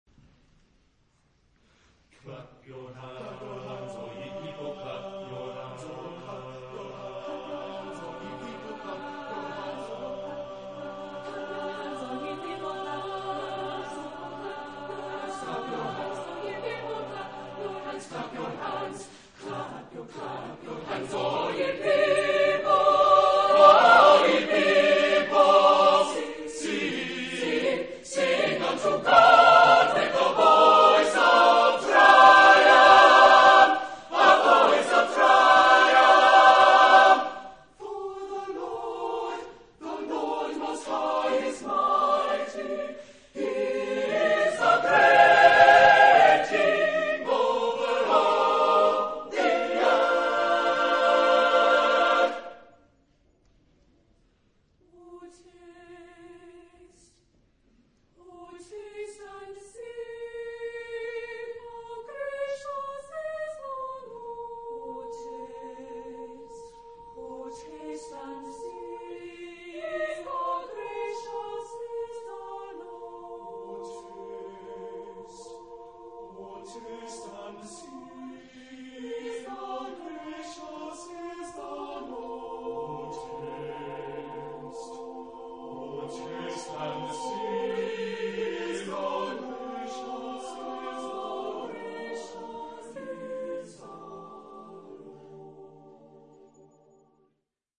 Carácter de la pieza : sincopado
Tipo de formación coral: SATB  (4 voces Coro mixto )
Instrumentación: Piano  (1 partes instrumentales)